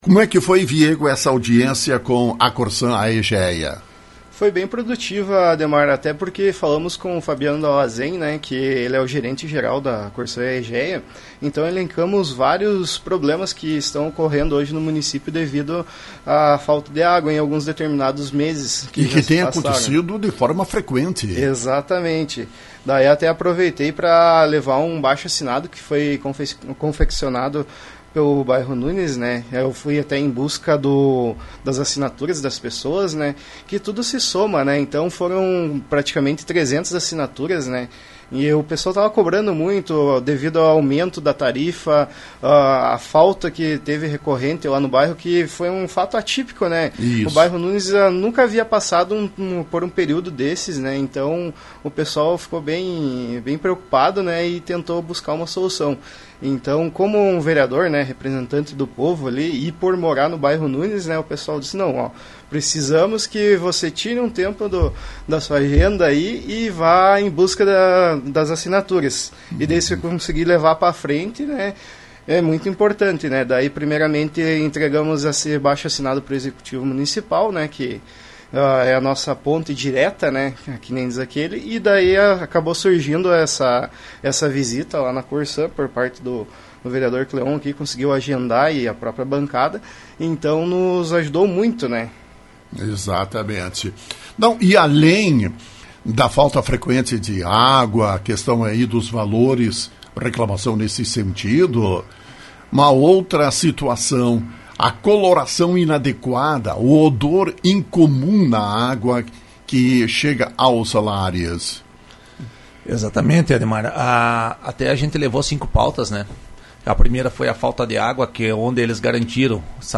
Vereadores Viego Silva dos Santos e Cleon Piva informaram, em entrevista, como foi a audiência.